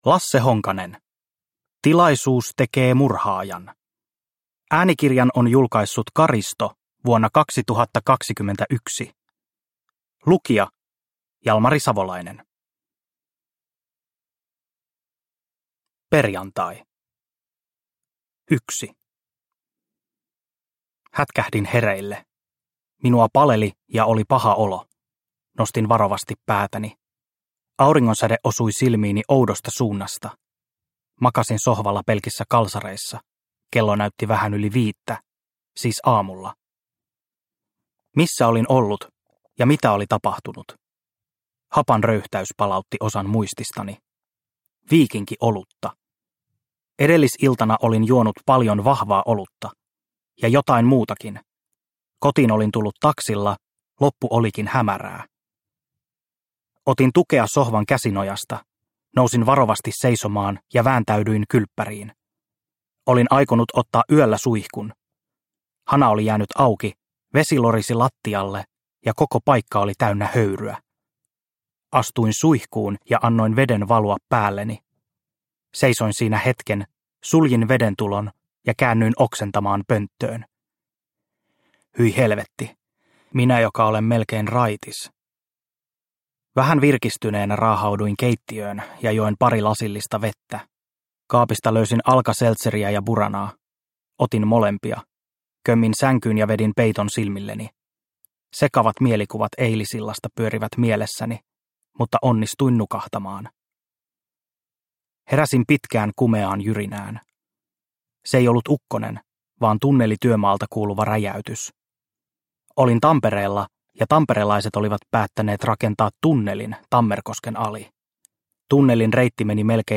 Tilaisuus tekee murhaajan – Ljudbok – Laddas ner